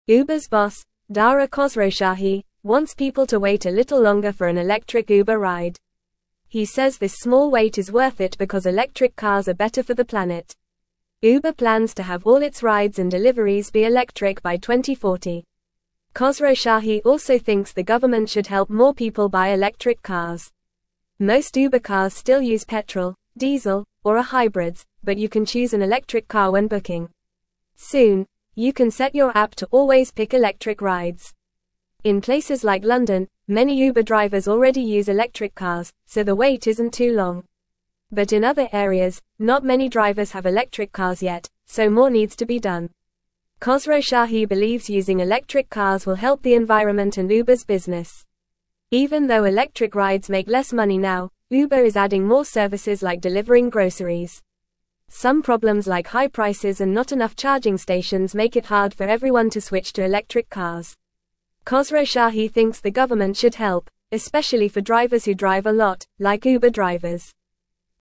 Fast
English-Newsroom-Lower-Intermediate-FAST-Reading-Uber-Boss-Wants-More-Electric-Cars-for-Rides.mp3